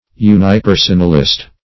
Search Result for " unipersonalist" : The Collaborative International Dictionary of English v.0.48: Unipersonalist \U`ni*per"so*nal*ist\, n. (Theol.) One who believes that the Deity is unipersonal.
unipersonalist.mp3